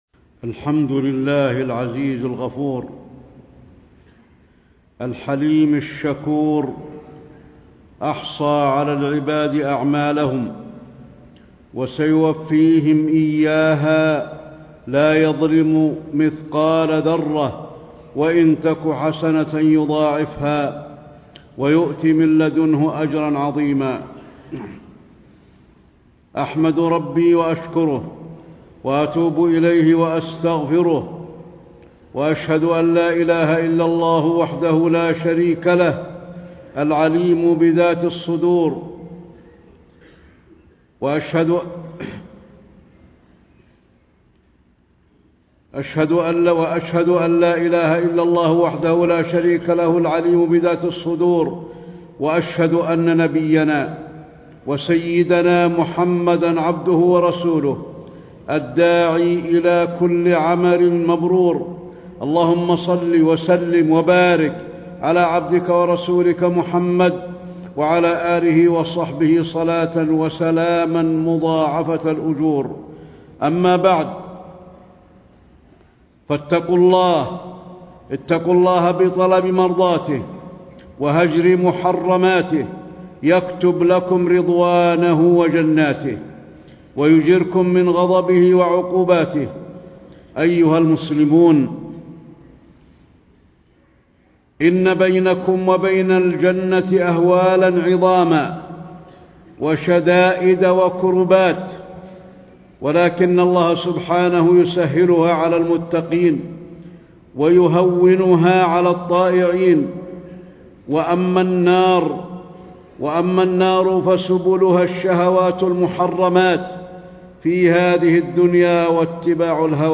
تاريخ النشر ١٣ جمادى الأولى ١٤٣٥ هـ المكان: المسجد النبوي الشيخ: فضيلة الشيخ د. علي بن عبدالرحمن الحذيفي فضيلة الشيخ د. علي بن عبدالرحمن الحذيفي الجنة والنار في الكتاب والسنة